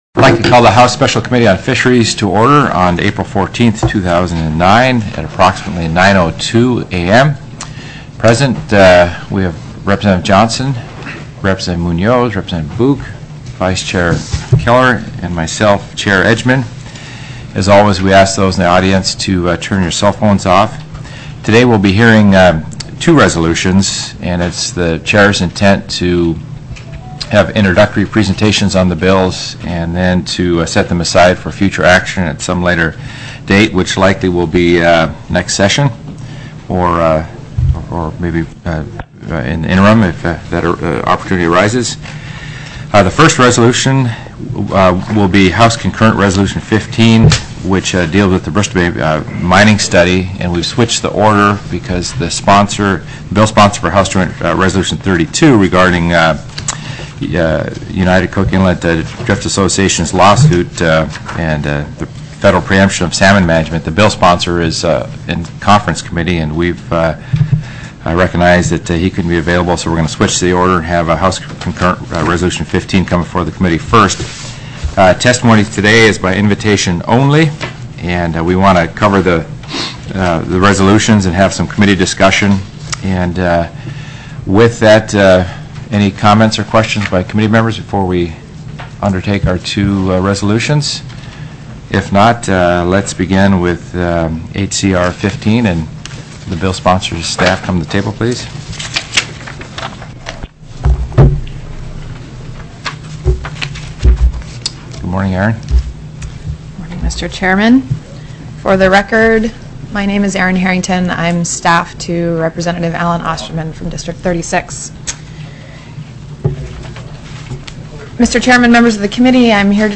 04/14/2009 09:00 AM House FISHERIES